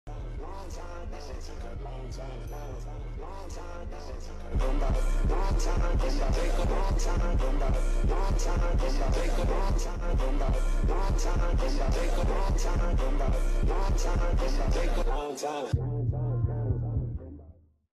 Quality is so bad but ignore that
You Just Search Sound Effects And Download. tiktok funny sound hahaha Download Sound Effect Home